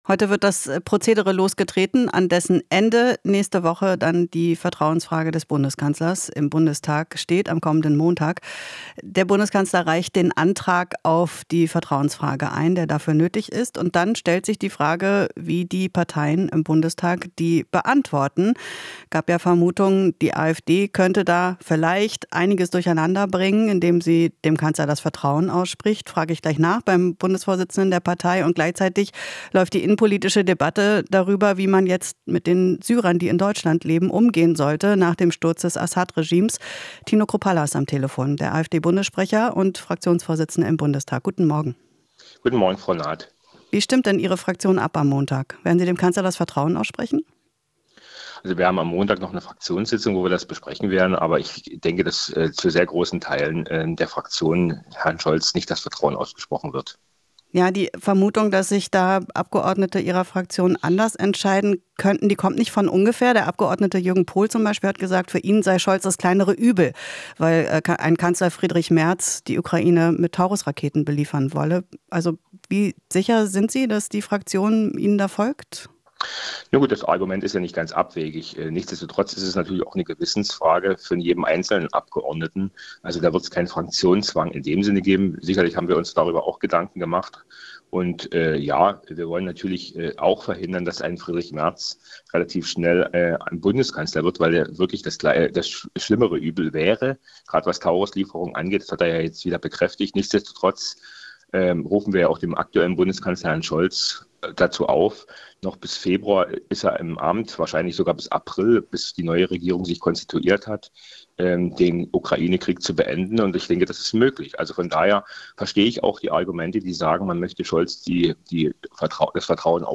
Tino Chrupalla, Co-Vorsitzender der AfD